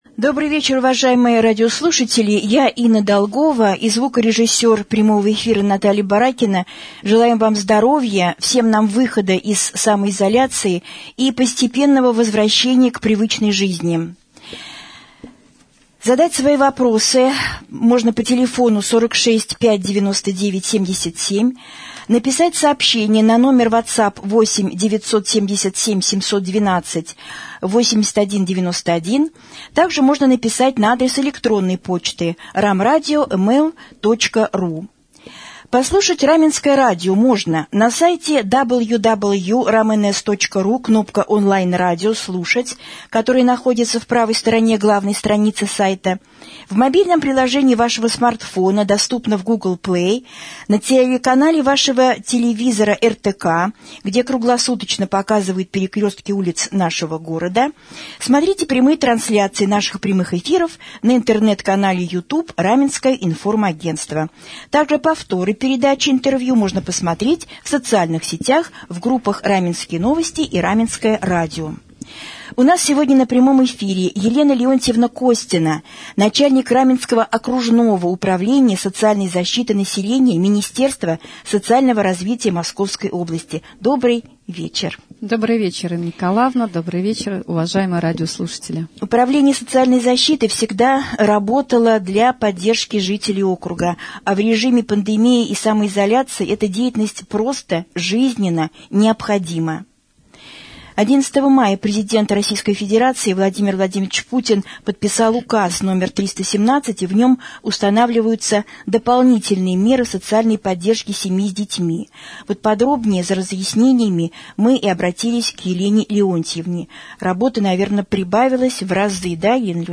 Начальник Раменского Управления социальной защиты населения стала гостем прямого эфира на Раменском радио 28 мая 2020 г.